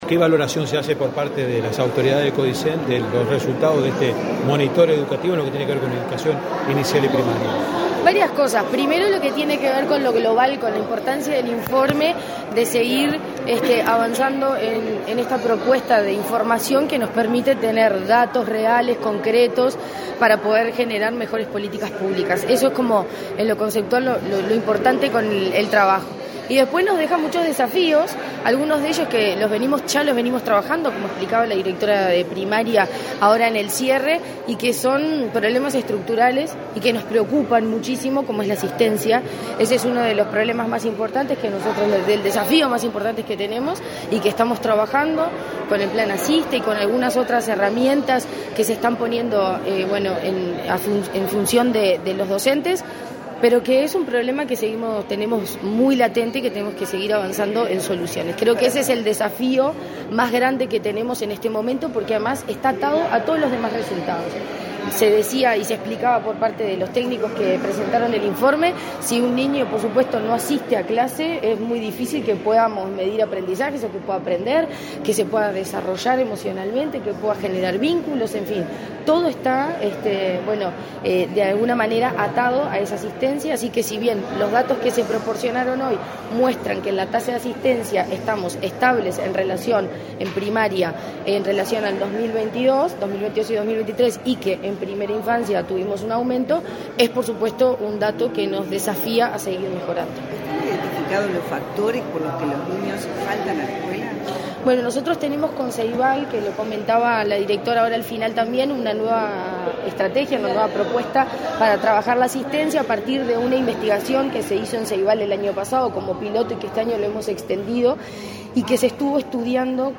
Declaraciones a la prensa de la presidenta de la ANEP, Virginia Cáceres
Tras participar en la presentación del Monitor de Educación Inicial y Primaria, este 13 de junio, la presidenta de la Administración Nacional de